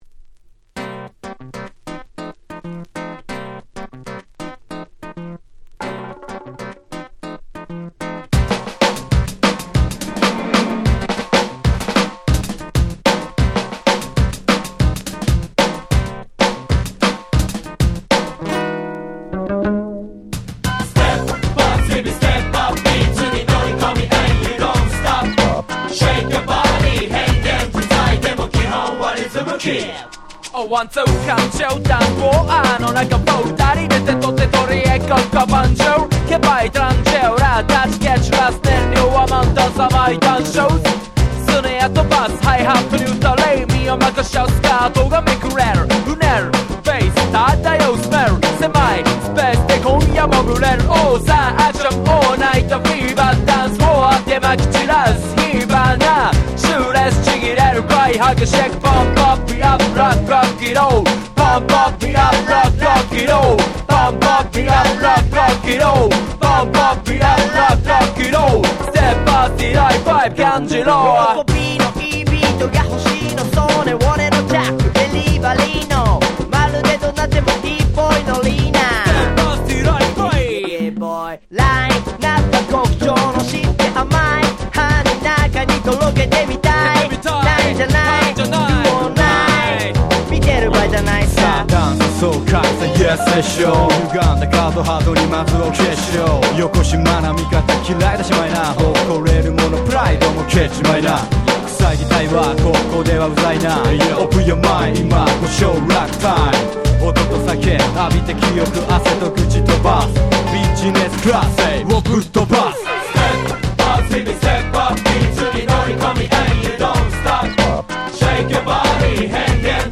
Japanese Hip Hop Classics !!